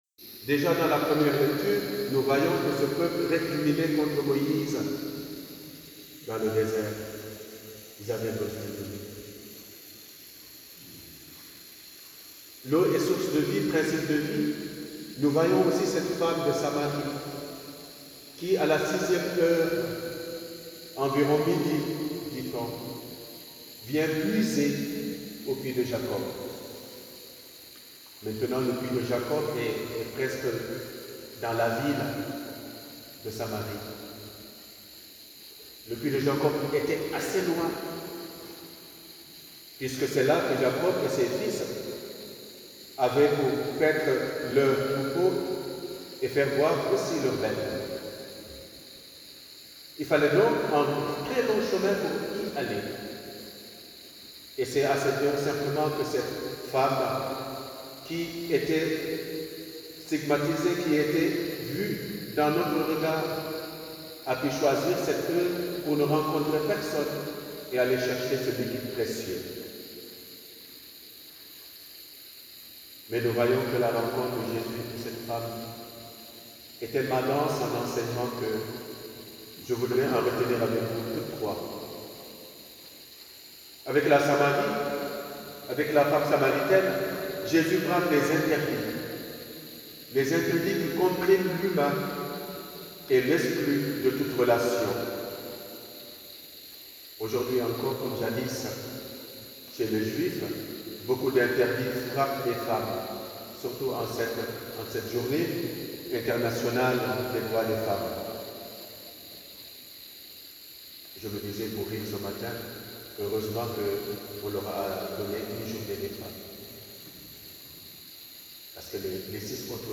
Homelie1.wav